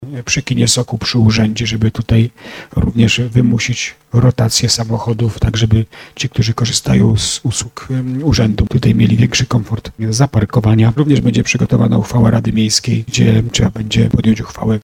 Jak mówił w trakcie sesji Rady Miejskiej w Starym Sączu, burmistrz Jacek Lelek, ma to ułatwić parkowanie kierowcom.